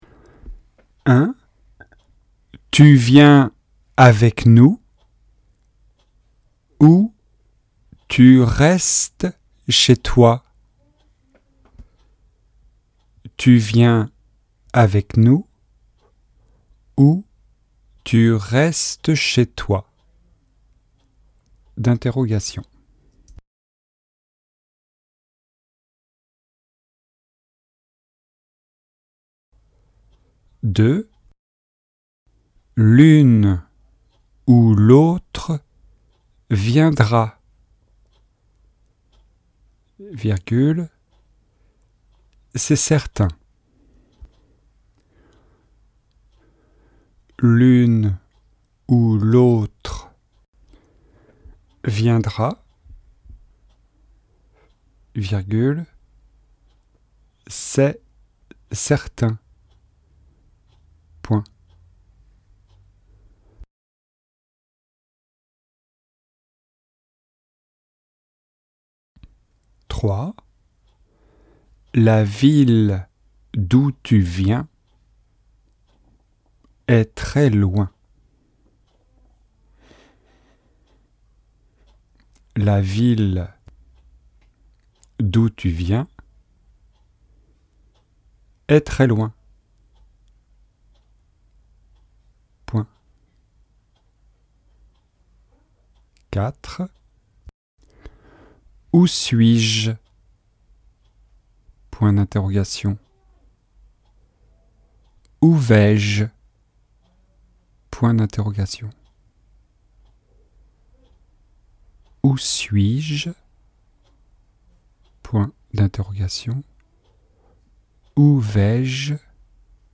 Dictée de phrases :
ou-ou-dictee.mp3